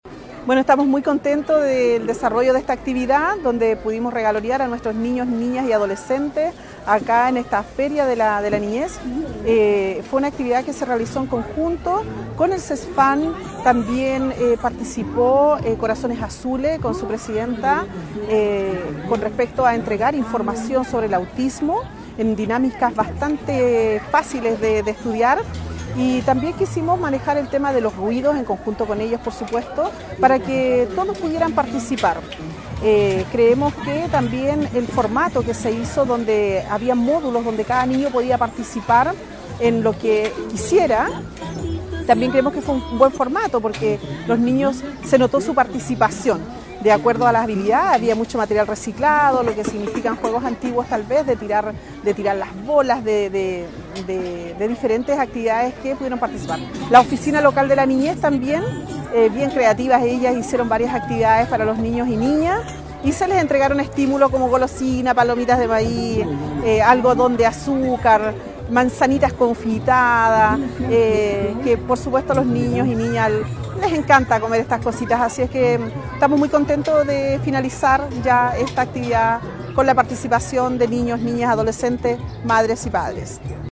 La alcaldesa destacó la masiva participación familiar en esta jornada en la plaza de Samo Alto.